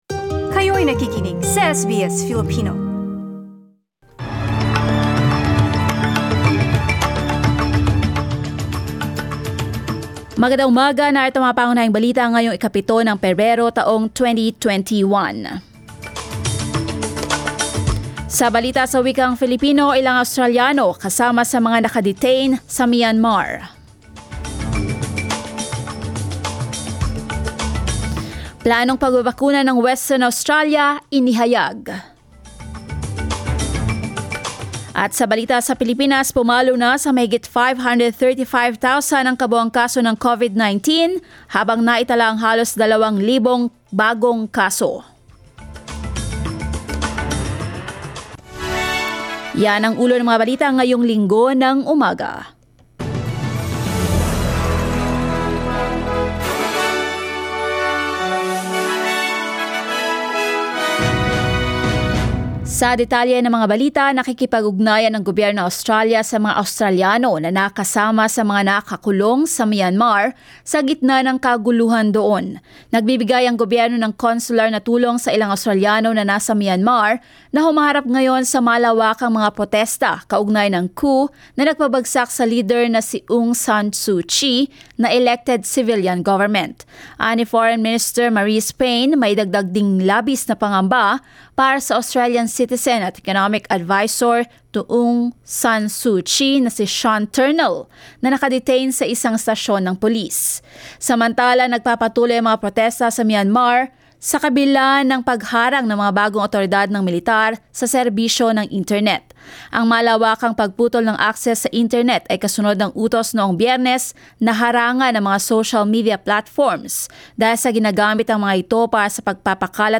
SBS News in Filipino, Sunday 7 February